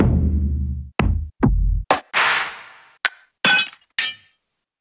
medieval kit for the riddim peasants: